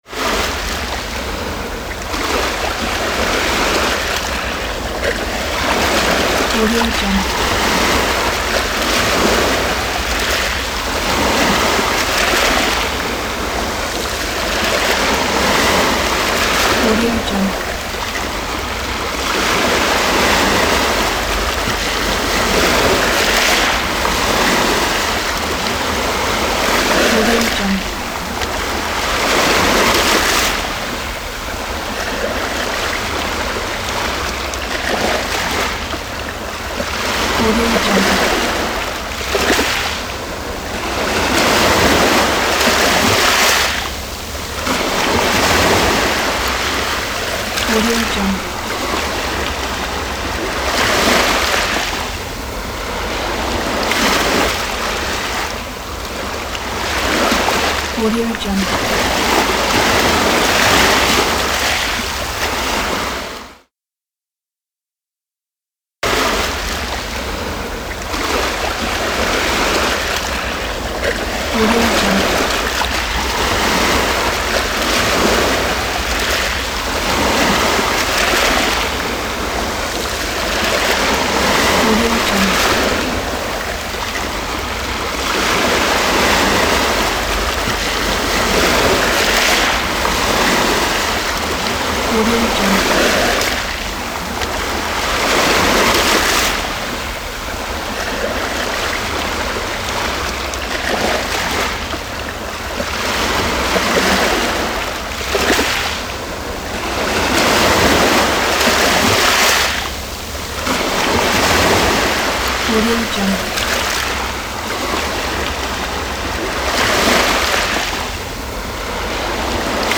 دانلود افکت صدای امواج اقیانوس دریا
Sample rate 16-Bit Stereo, 44.1 kHz
Looped Yes